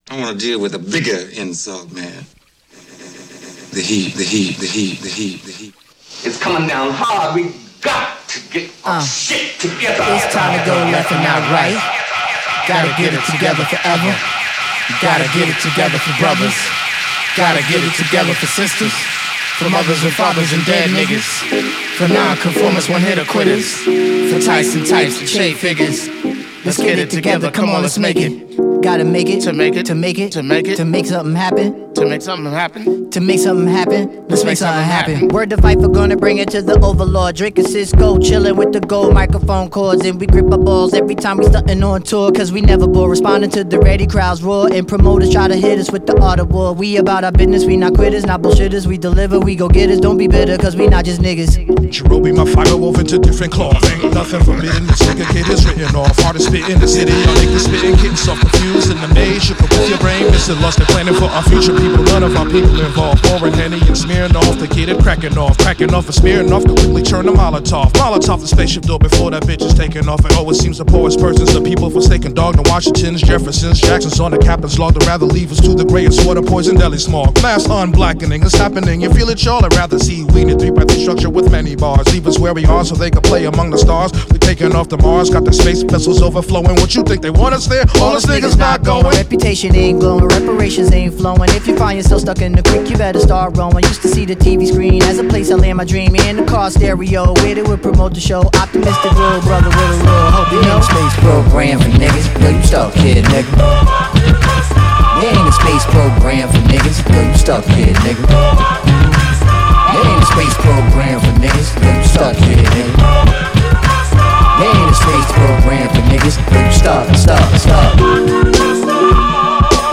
There are modern flourishes all over the album